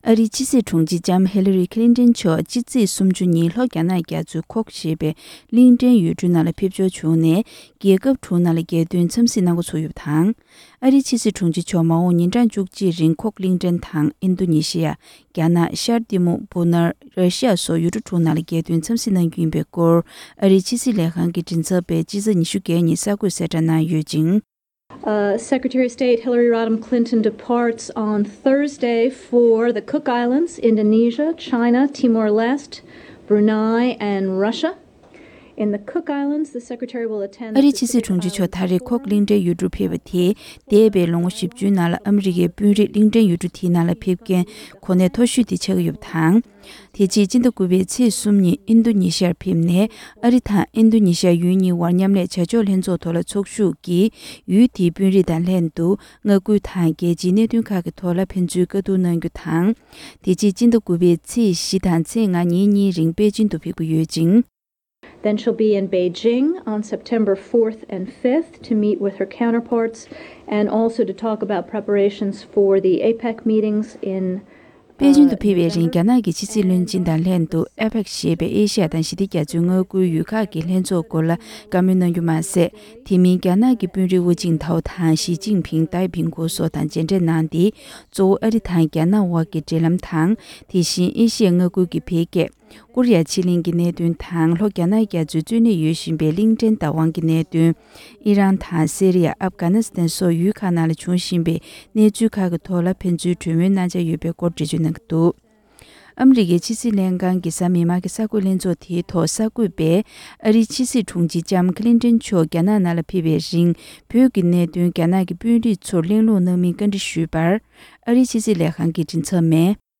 ༄༅། །འདི་རིང་གི་གསར་འགྱུར་དང་འབྲེལ་བའི་ལེ་ཚན་གྱི་ནང་།